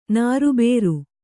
♪ nāru bēru